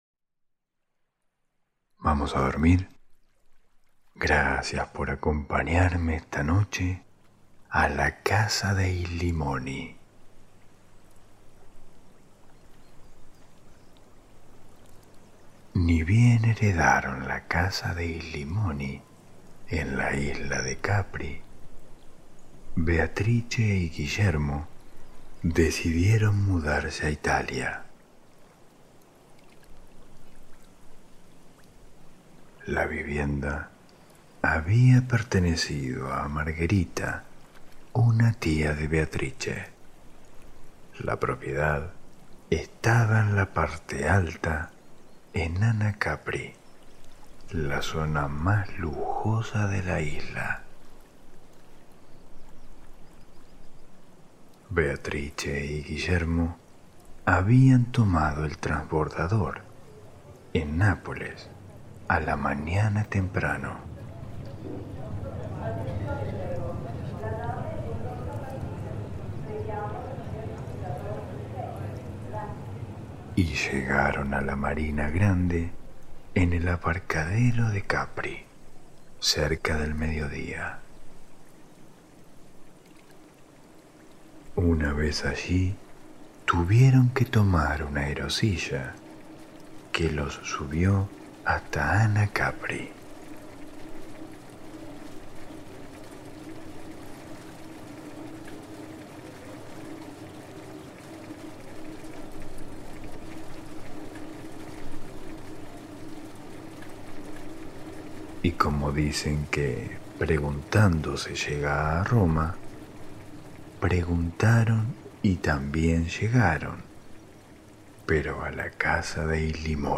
Cuentos ASMR para dormir - La Casa dei Limoni ✨